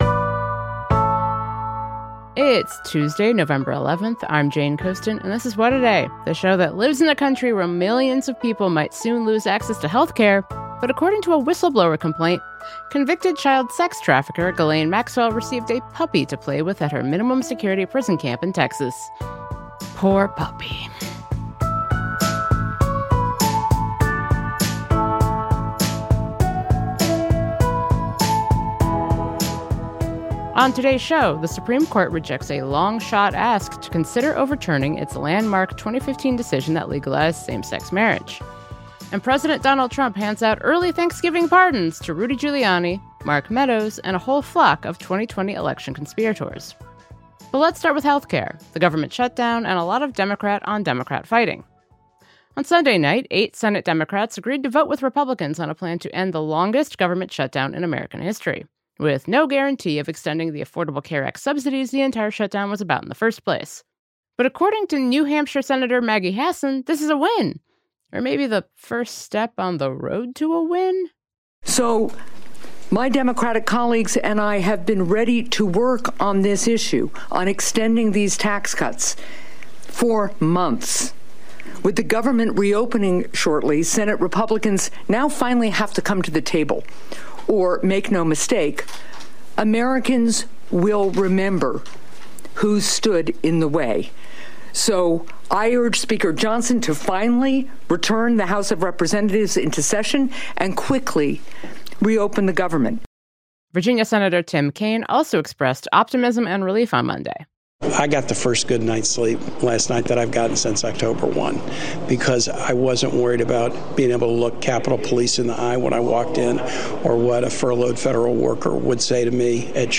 Vermont Independent Senator Bernie Sanders has been one of the toughest critics of the Democrats who caved. We spoke with the senator ahead of Monday night’s vote about the shutdown, healthcare, and why he thinks the fight is nowhere near